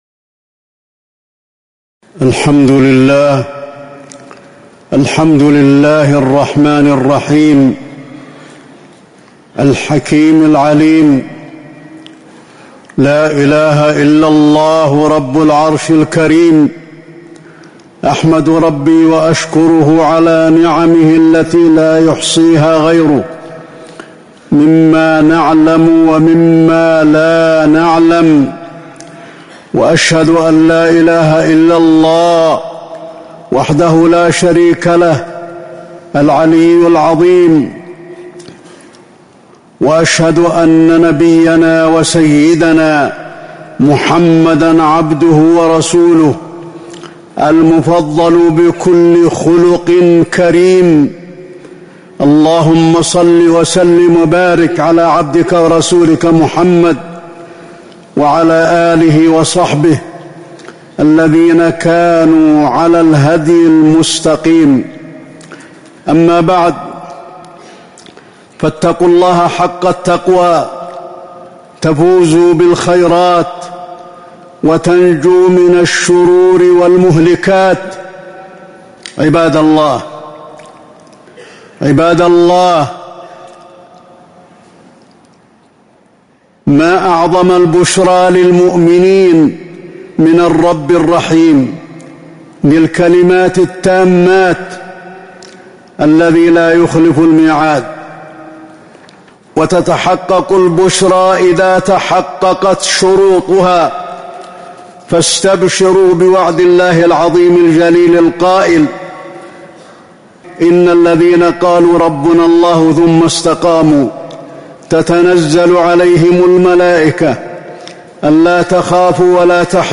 تاريخ النشر ١٧ شوال ١٤٤٥ هـ المكان: المسجد النبوي الشيخ: فضيلة الشيخ د. علي بن عبدالرحمن الحذيفي فضيلة الشيخ د. علي بن عبدالرحمن الحذيفي من فضائل الاستقامة